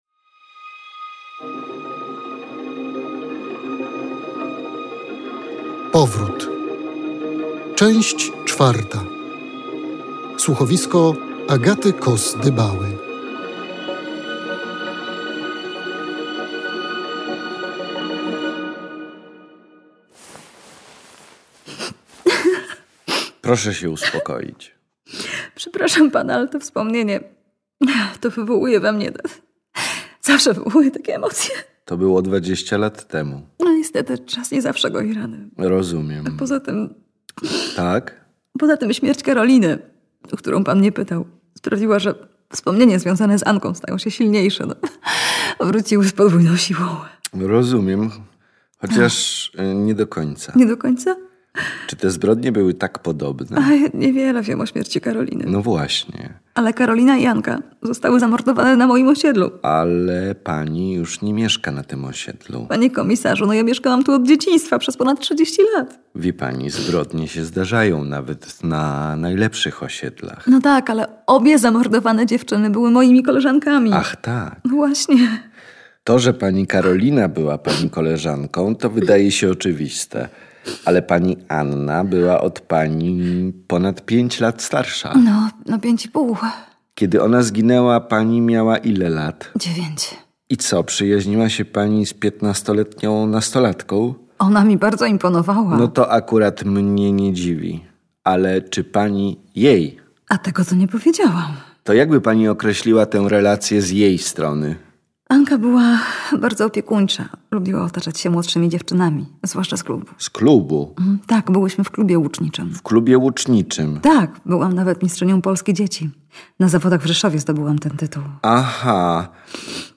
Czwarta część słuchowiska znacznie przybliży nas do odpowiedzi.